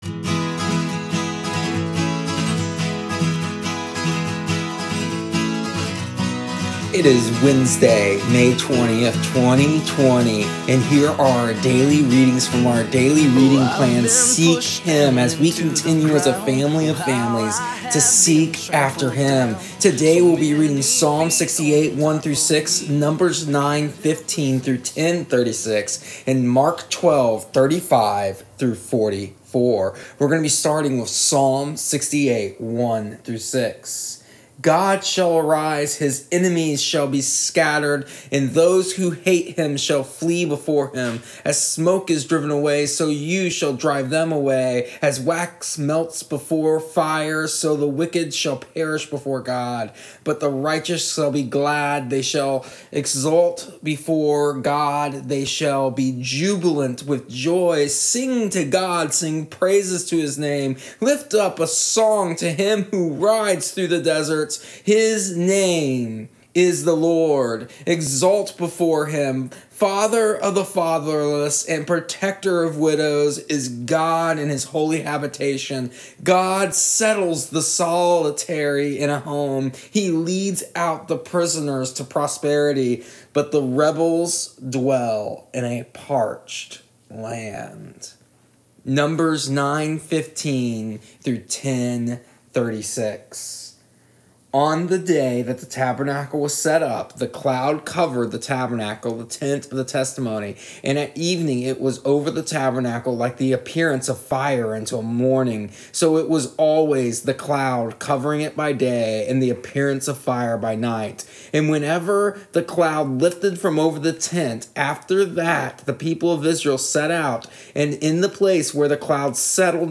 Here are our daily readings for May 20th, 2020 in audio format from our daily reading plan seek HIm.